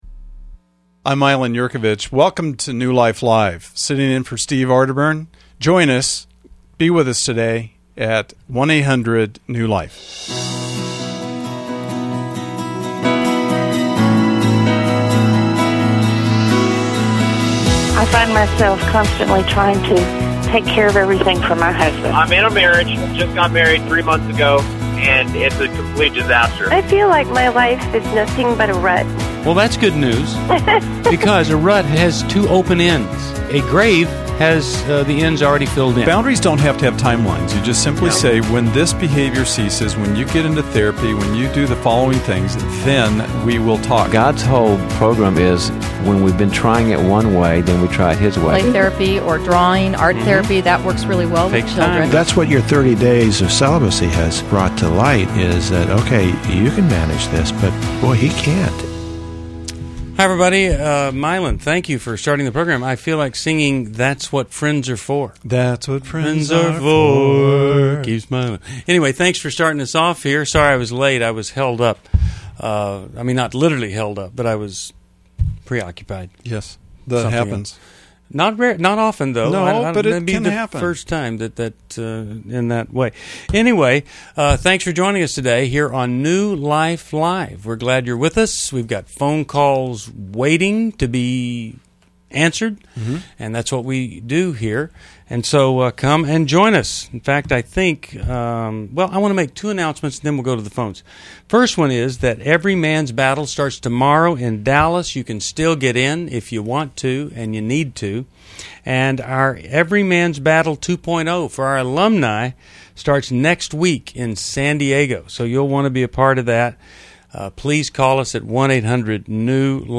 Explore love addiction, boundaries, and marriage challenges in this episode of New Life Live: October 13, 2011, featuring expert insights from our hosts.
Caller Questions: 1.